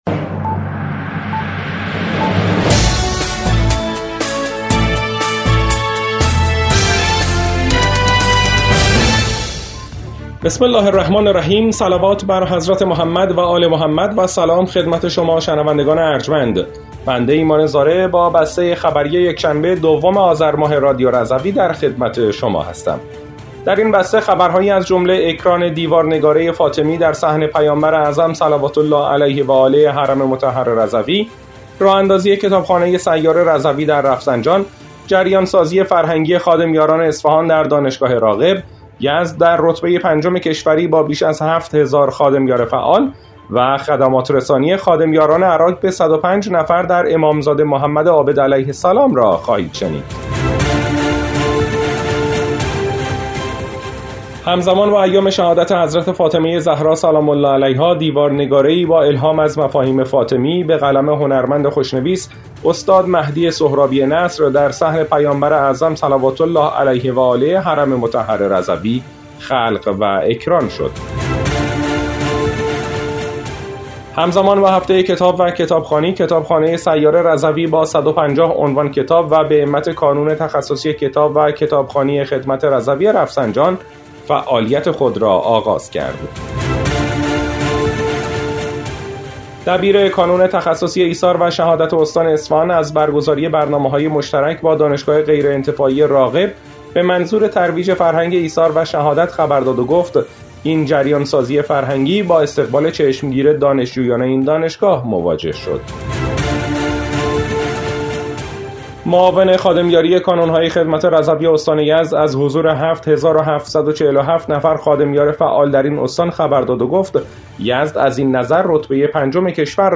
بسته خبری ۲ آذر ۱۴۰۴ رادیو رضوی؛